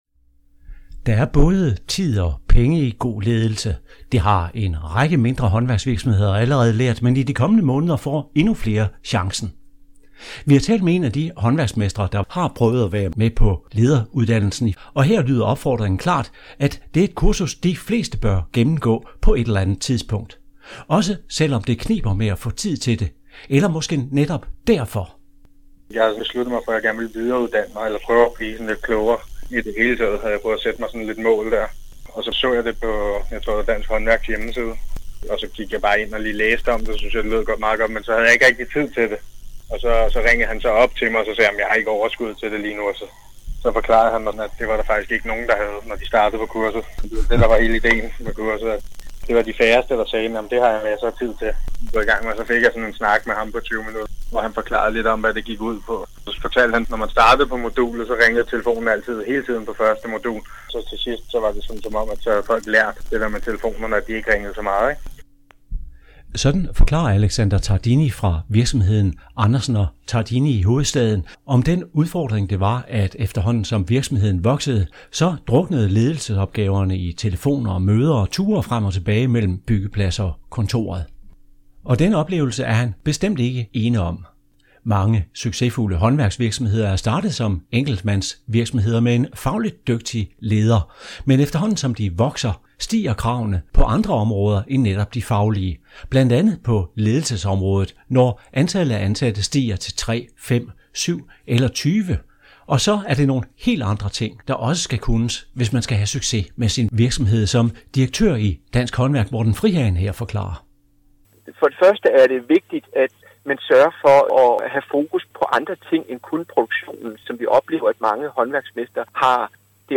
Og har du ikke allerede deltaget, bør du tage dig tid til at komme med – for det betaler sig, som du kan høre her, hvor vi taler med et medlem, der tog uddannelsen i foråret 2019.